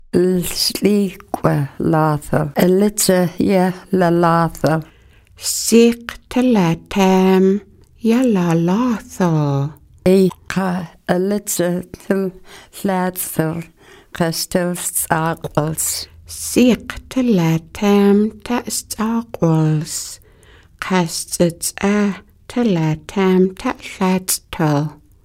Listen to the elder